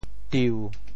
“住”字用潮州话怎么说？
住 部首拼音 部首 亻 总笔划 7 部外笔划 5 普通话 zhù 潮州发音 潮州 zu6 文 diu7 白 中文解释 住 <动> (形声。